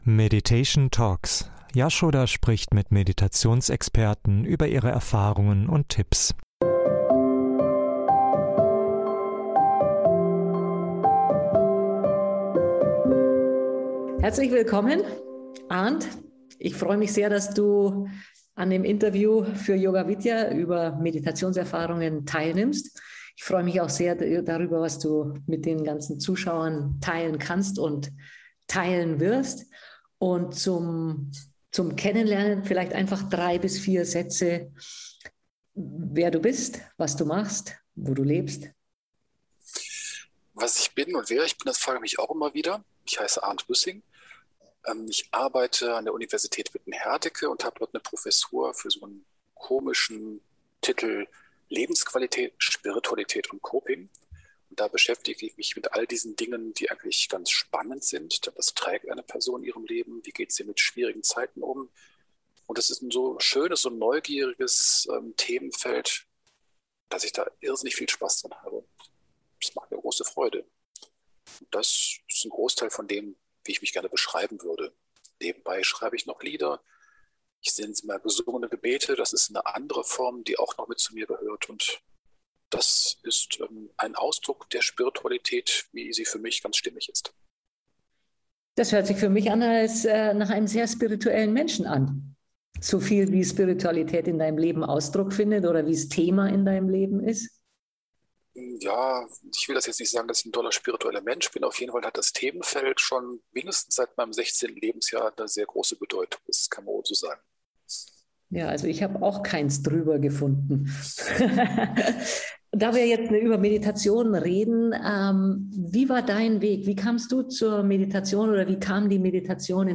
In diesem Interview erzählt er von seinem eigenen Weg zur Zen-Meditation und den wissenschaftlichen Hintergründen von Meditation und Achtsamkeit.